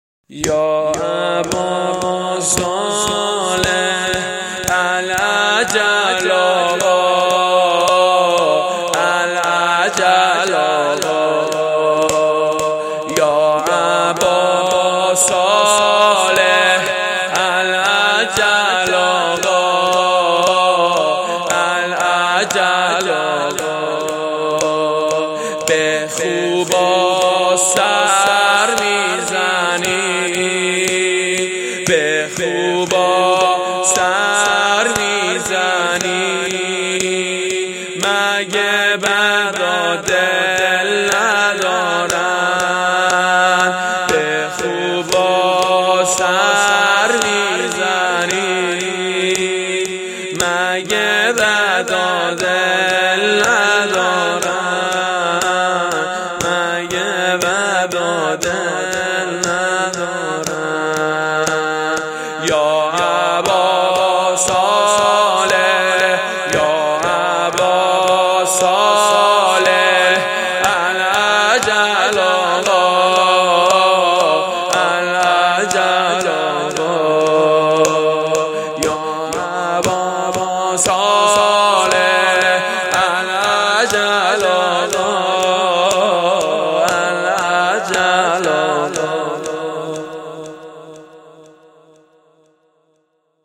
توسل به امام زمان (عج ) زمینه/ العجل آقا ویژه شهادت امام حسن عسکری (ع )(حسینیه ریحانة الحسین (س)